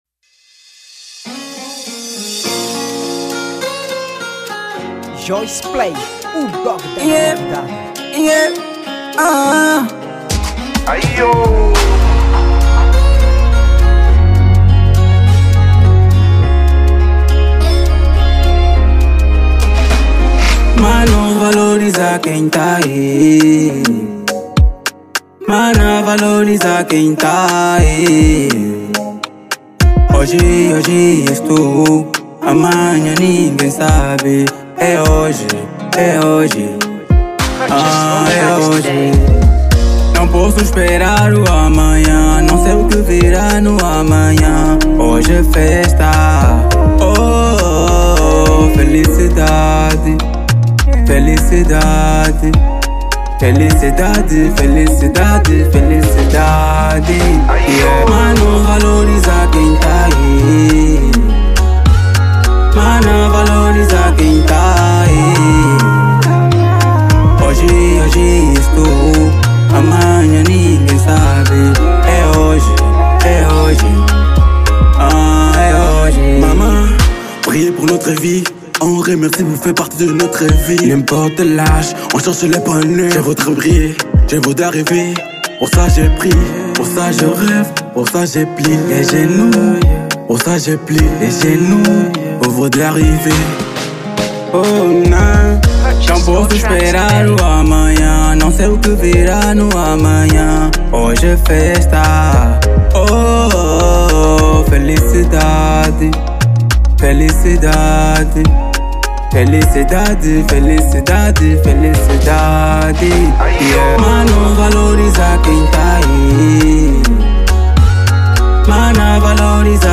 Género: Afro Naija